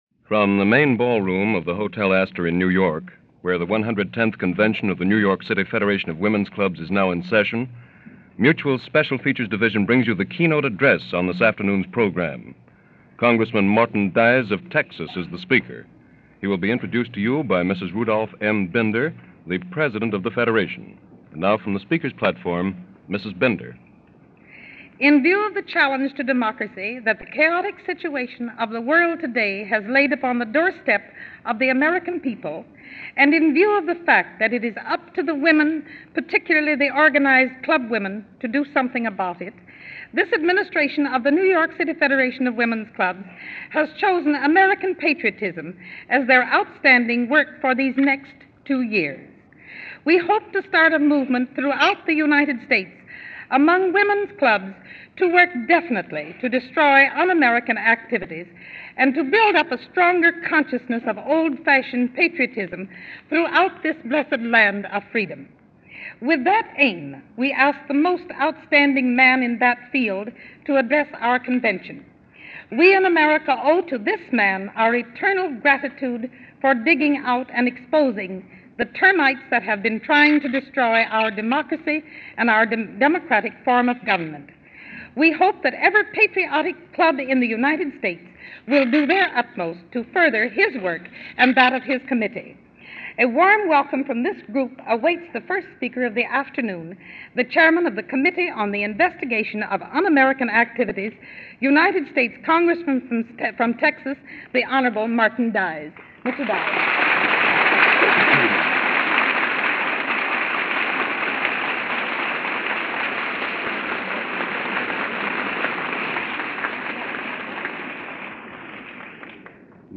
Martin Dies - Ferreting Out Subversives - Communism In America - Address To New York City Federation of Women's Clubs - October 27, 1939.
– MBS – Martin Dies Address – October 27, 1939 – Gordon Skene Sound Collection –